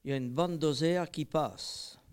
Localisation Sallertaine
Catégorie Locution